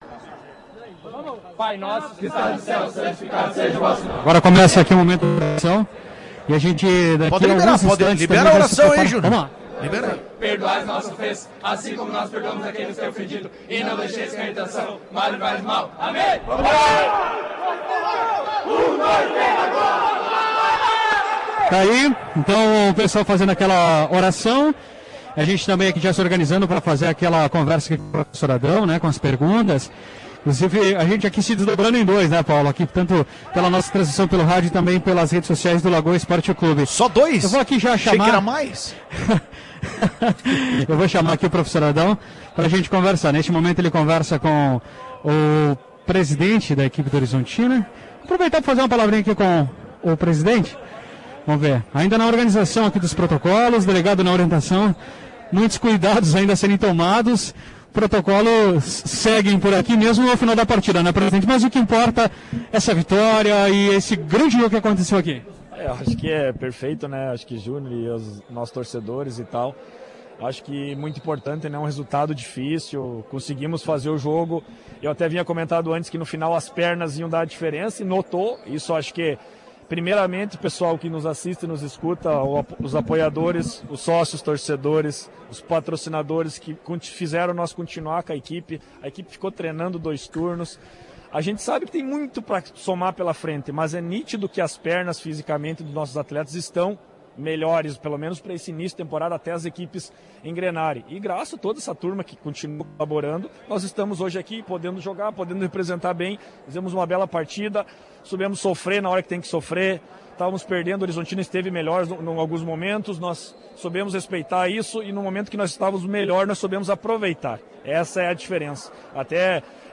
Ouça a entrevista ou leia o resumo da partida.